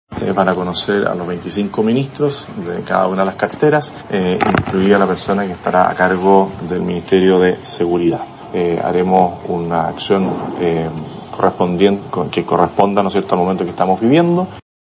Pese a la incertidumbre, el Presidente electo confirmó que dará a conocer el gabinete completo y que, por ende, el titular de Seguridad estará incluido.